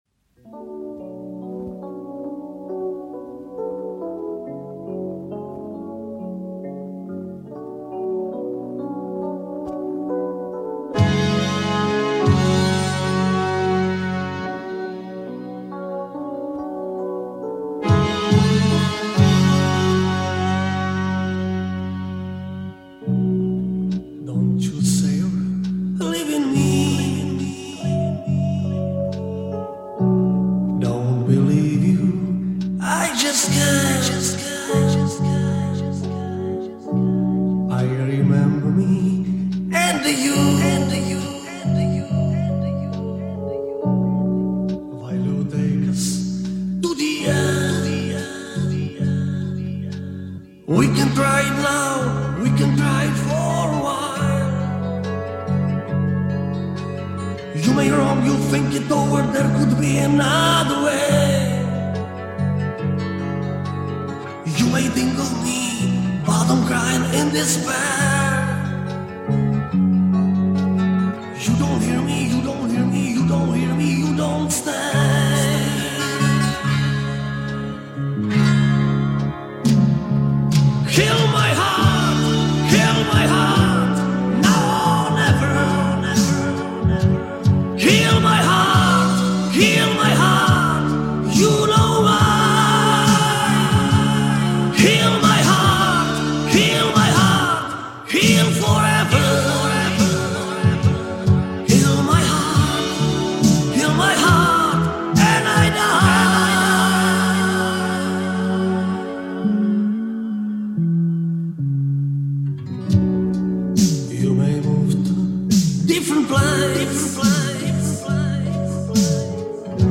Так было оцифровано моим приятелем с винила.